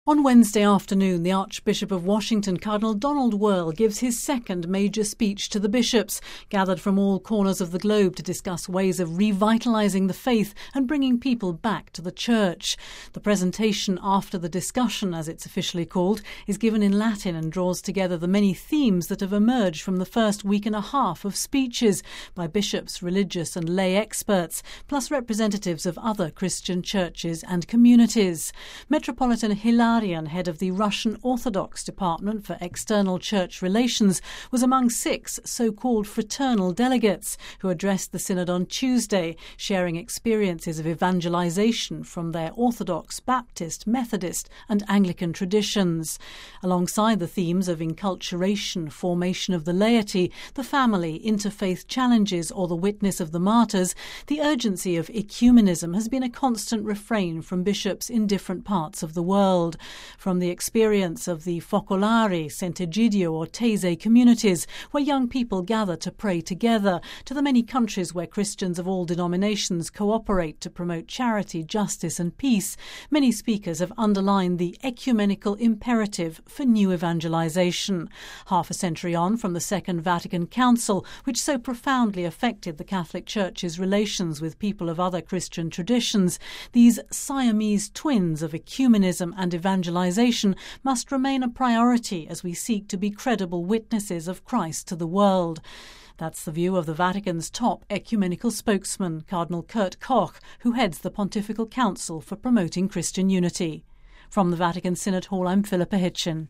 (Vatican Radio) The 15th General Congregation of the Synod of Bishops took place in the Vatican on Wednesday morning, marking the last session of speeches before the bishops gather in small language groups to start discussing propositions for the final synod document. Our special correspondent